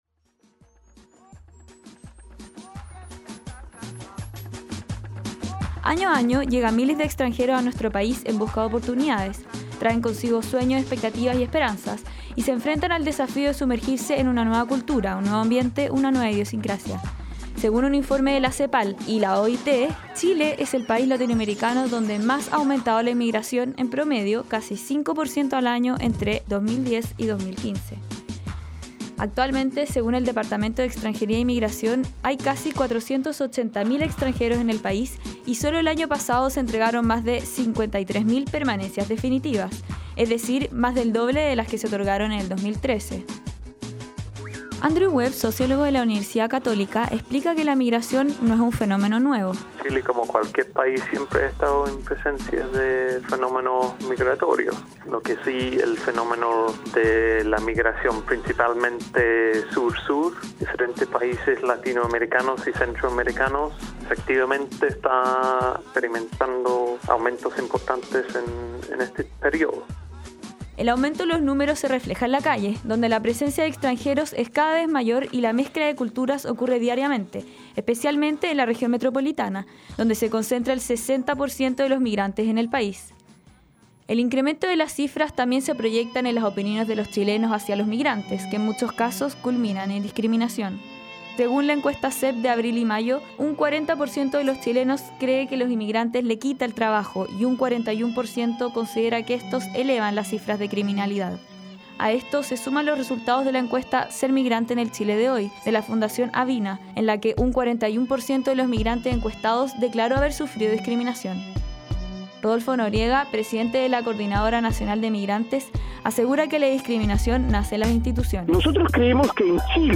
Para más información podrán escuchar el reportaje adjunto a continuación: http